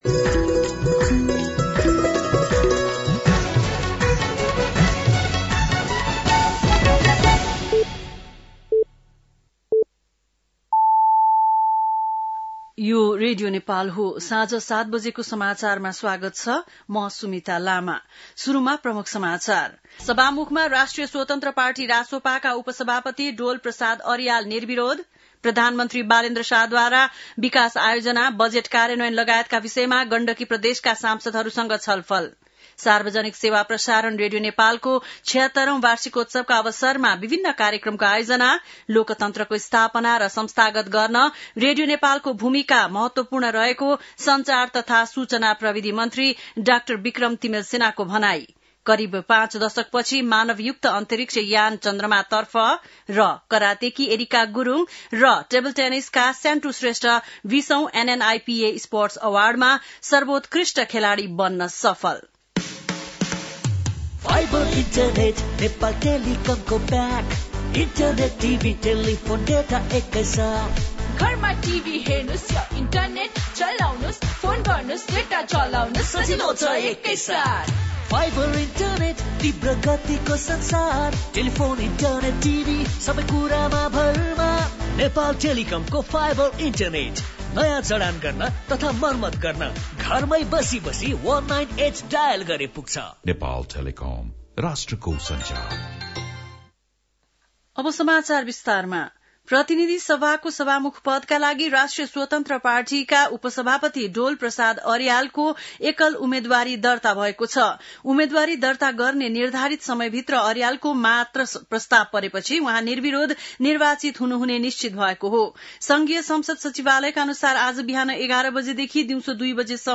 बेलुकी ७ बजेको नेपाली समाचार : २० चैत , २०८२
7-PM-Nepali-NEWS-12-20.mp3